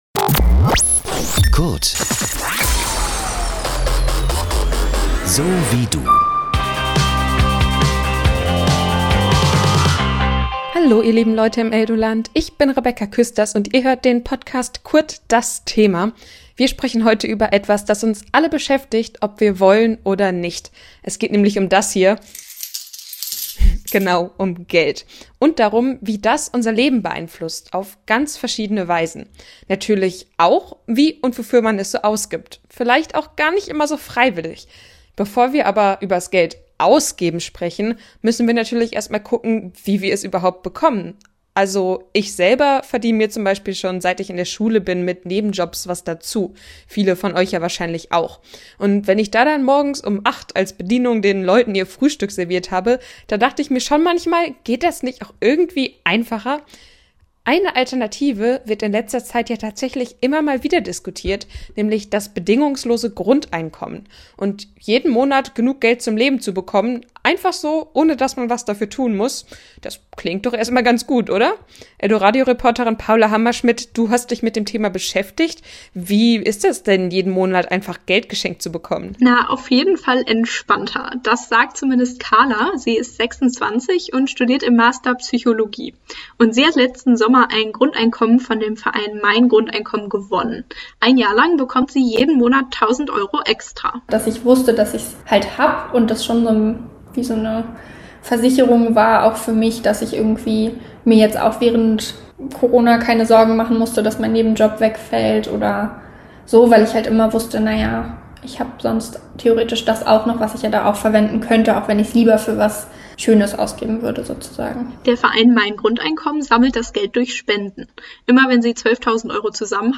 Wir haben uns getraut und einen Podcast nur über dieses unangenehme Thema gemacht, aber keine Sorge: Es gibt auch direkt einen Leitfaden dazu, wie Ihr das Thema Geld beim nächsten Mal leichter bei Euren Eltern zum Beispiel ansprechen könnt. Außerdem haben unsere eldoradio-Reporter*innen Menschen aufgespürt, die durch ihre Sucht so viel Geld ausgegeben haben, dass es nicht mehr gesund war - sie sprechen offen mit uns. Auch wie wir mit wenig Geld gut auskommen können, hört ihr in dieser Ausgabe des Podcasts.